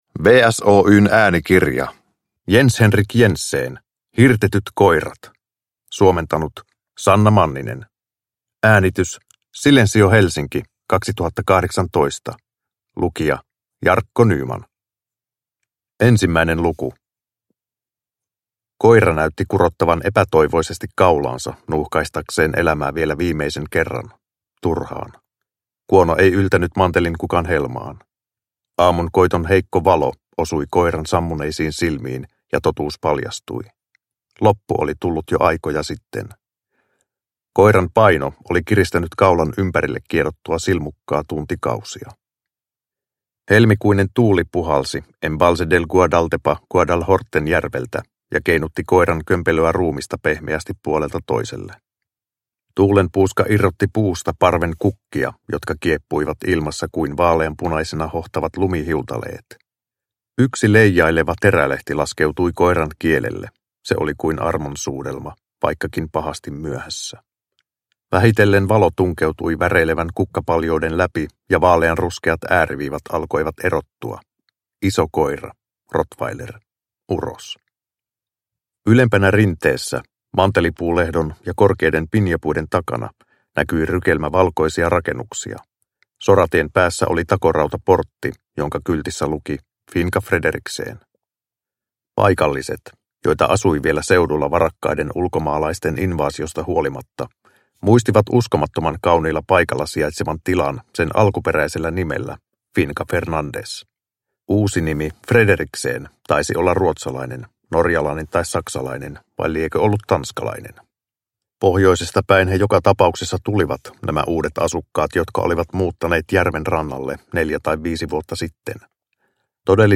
Hirtetyt koirat – Ljudbok – Laddas ner